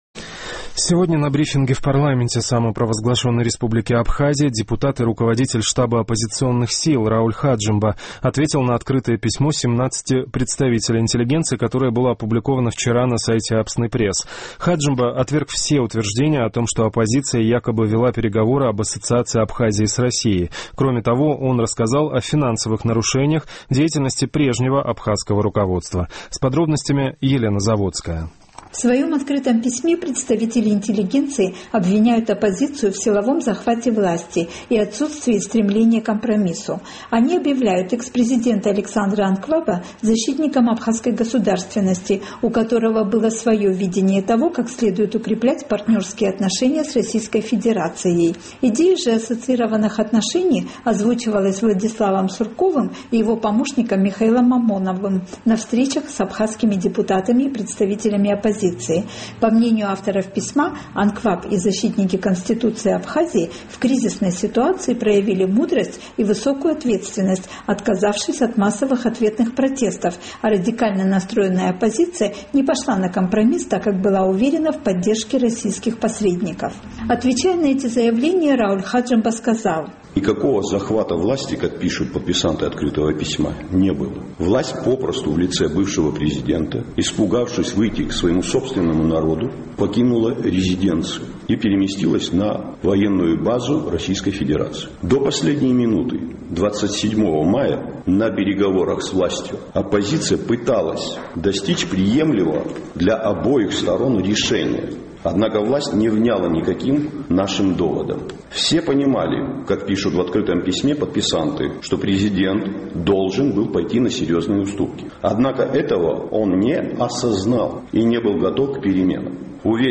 На брифинге в парламенте Абхазии Рауль Хаджимба ответил на открытое письмо представителей интеллигенции.